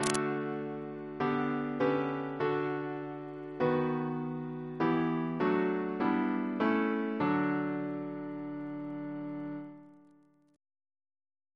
Single chant in D Composer: Edward F. Rimbault (1816-1876) Reference psalters: OCB: 9